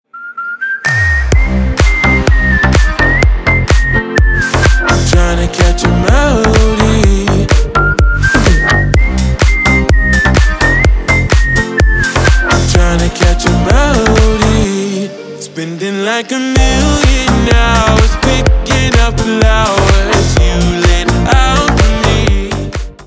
• Качество: 320, Stereo
свист
Electronic
EDM
Club House
качающие
Стиль: club house